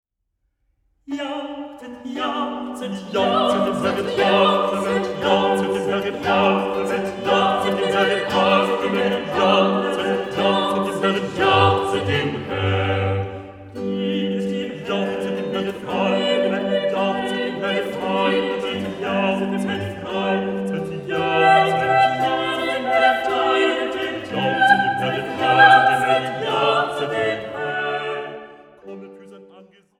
Sopran
Tenor
Harfe
Theorbe
Orgel